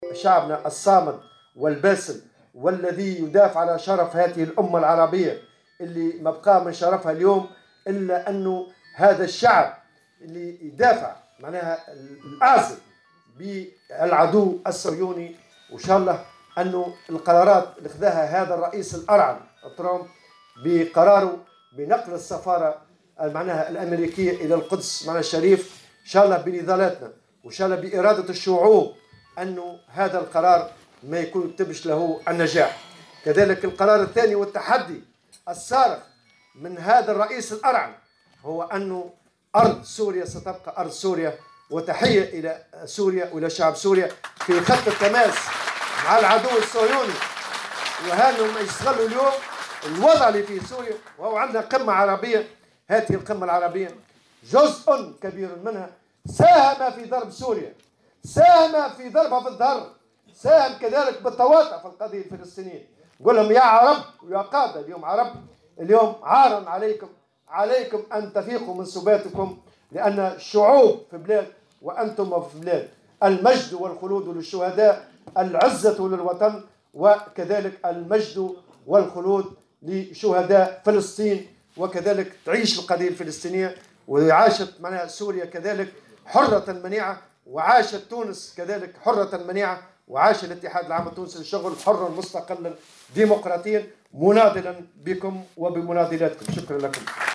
واعتبر الطبوبي في تصريح اليوم الخميس على هامش انعقاد المؤتمر العادي للجامعة العامة للصناديق الاجتماعية، بالحمامات أن جزءا من القمم العربية ساهم في ضرب سوريا والتواطؤ ضدّ القضية الفلسطينية، قائلا "يا عرب عار عليكم".